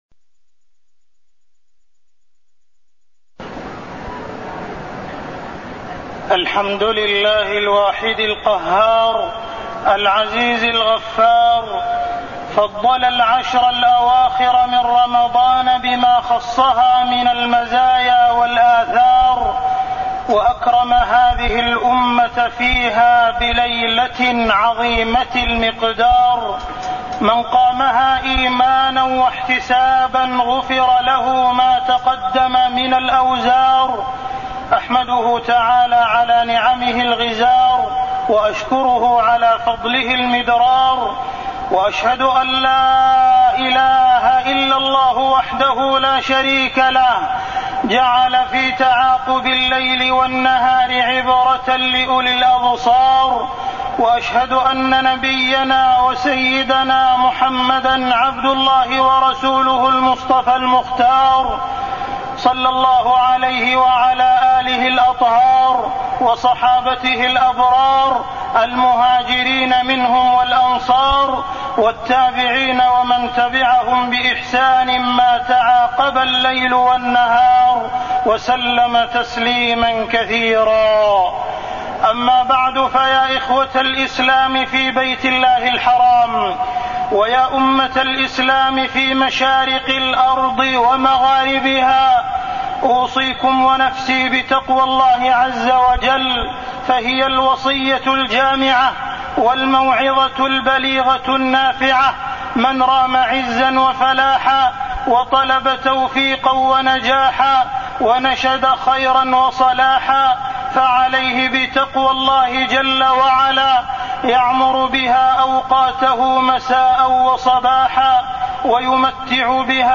تاريخ النشر ٢٦ رمضان ١٤٢١ هـ المكان: المسجد الحرام الشيخ: معالي الشيخ أ.د. عبدالرحمن بن عبدالعزيز السديس معالي الشيخ أ.د. عبدالرحمن بن عبدالعزيز السديس العشر الأواخر من رمضان